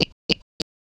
Bunny Sound Effects - Free AI Generator & Downloads
Single Bunny Hop: A single, soft hop of a small bunny, 0.5 seconds." 0:01